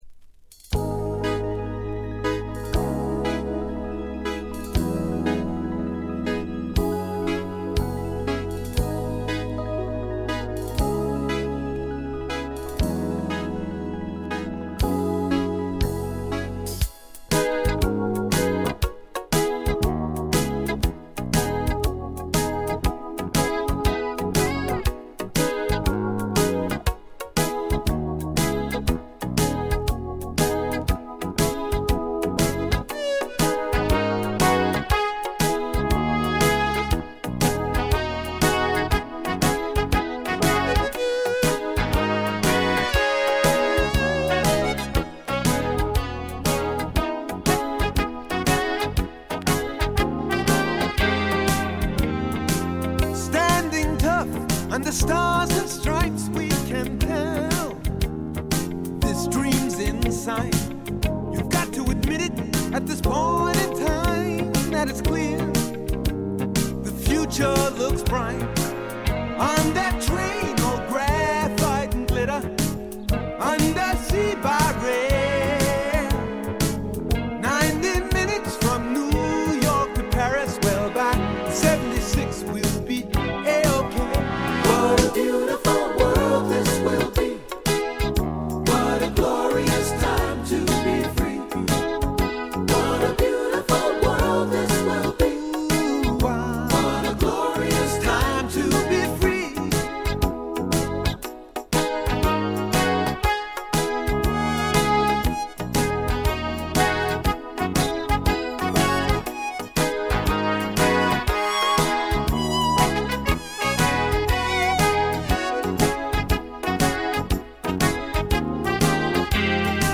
ホーム > レコード：ポップ / AOR
部分試聴ですが、ごくわずかなノイズ感のみ。
試聴曲は現品からの取り込み音源です。